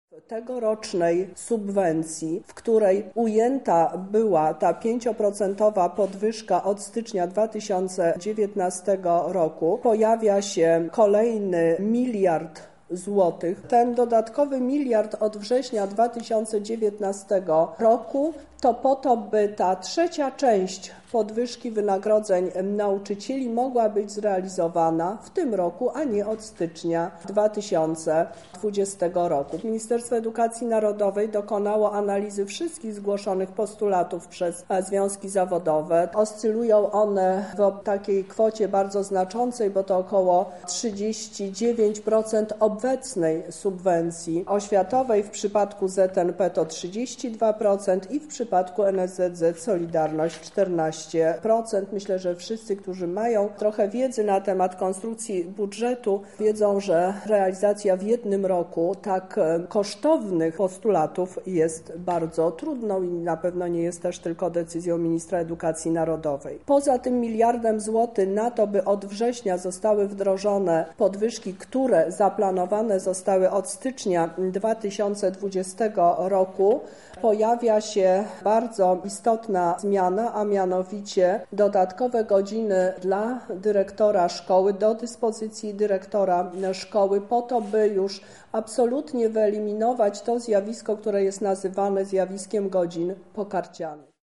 Na dzisiejszej konferencji prasowej Teresa Misiuk, kurator oświaty w Lublinie, zapewniła że dodatkowe środki finansowe zostaną przyznane dla pedagogów już od 1 września.